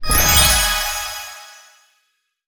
healing_magic_spell_01.wav